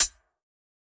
Closed Hats
HIHAT_GRITS.wav